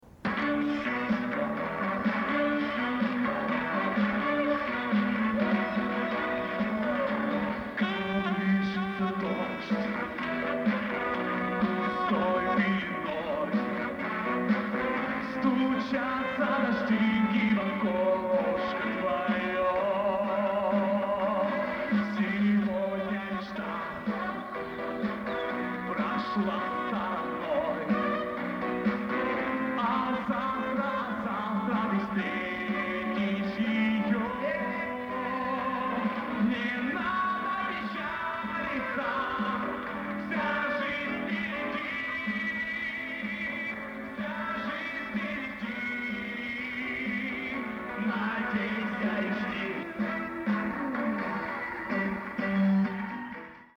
(1/2 финала 2005 Лиги КВН-Сибирь)